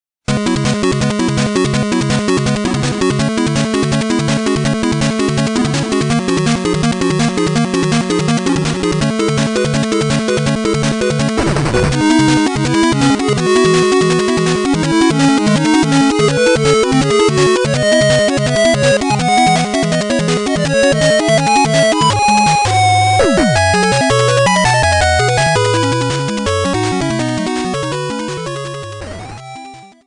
Clipped to 30 seconds and applied fade-out
Fair use music sample